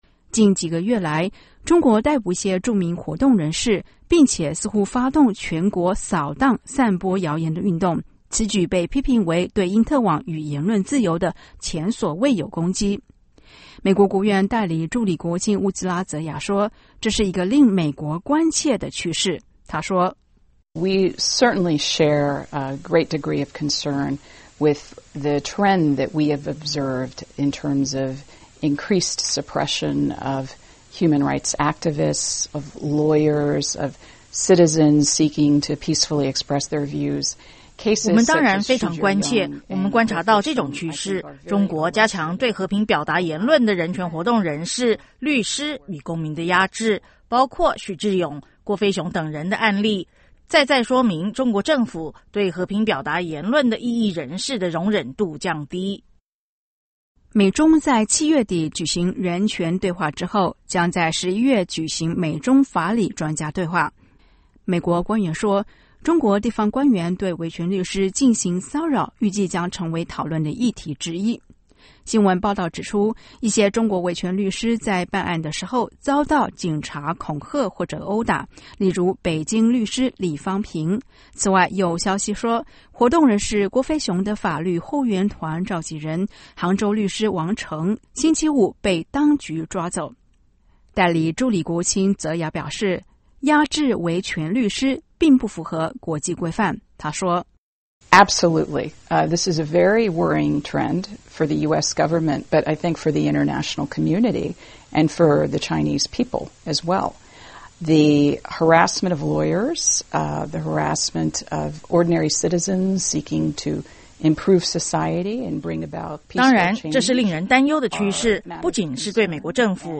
专访美人权事务高官：关切中国压制言论自由
华盛顿 —  美国国务院主管民主、人权和劳工事务的代理助理国务卿乌兹拉•泽雅(Uzra Zeya)星期五(9月6日)接受美国之音专访。